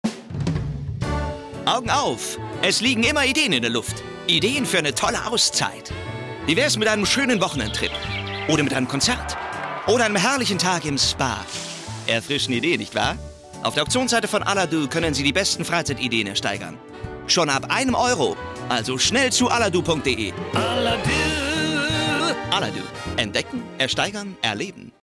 Sprecher deutsch, seriös, sympathisch, mittelkräftig, facettenreich
Sprechprobe: Industrie (Muttersprache):